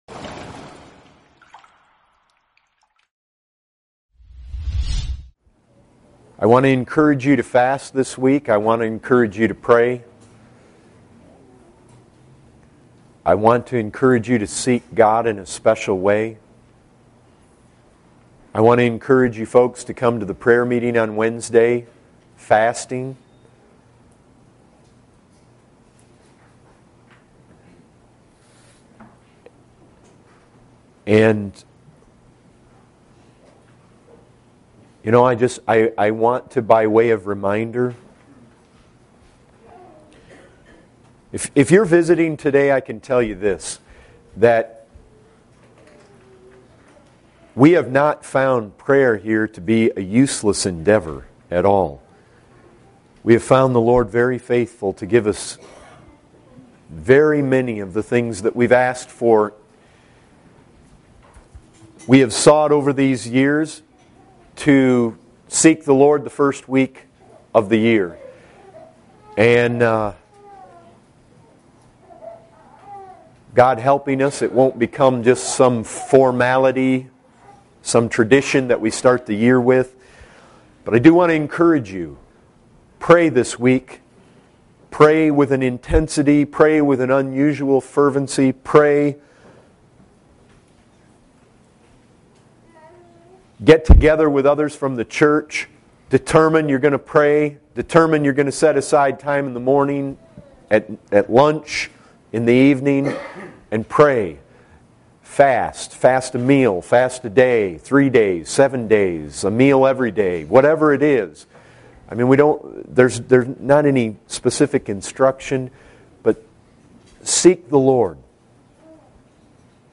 Preached in 2011 at Grace Community Church